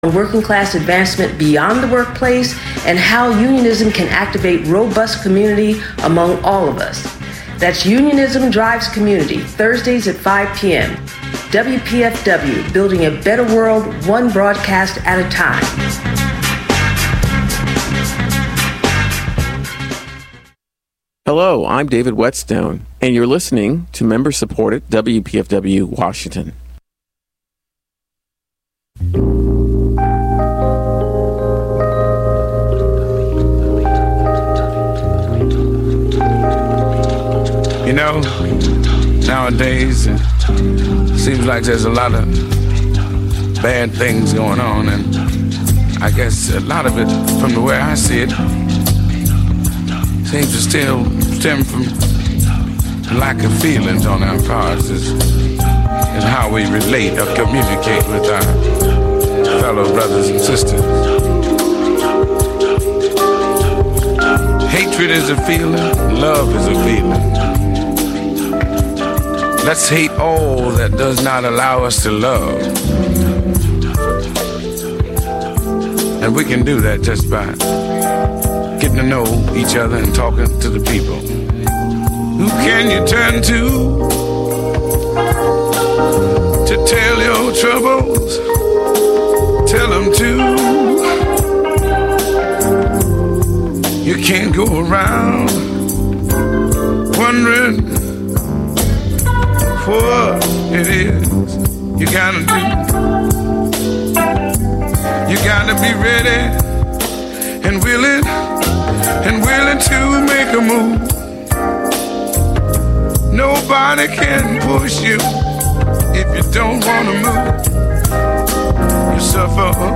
An open forum for debating local, global, social, entertainment, and political news.